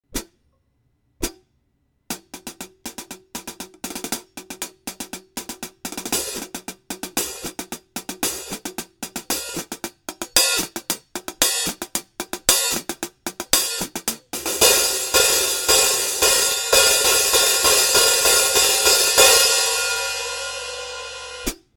Je suis pas très fort pour décrire le son d'une cymbale… mais je l'aime bien ce Hihat, je lui trouve une sonorité assez brillante sans être trop métallique, je trouve qu'il a pas mal de corps, et j'aime bien le son semi ouvert.
pour lui trouver un défaut je dirais qu'il manque parfois un peu de nervosité, de réactivité, je pense que les modèles soundwave sont plus réactifs, par contre je trouve leur son un peu "maigre".
Pour te donner une idée du son, c'est dans la même veine que les Hihat A Custom de Zildjian.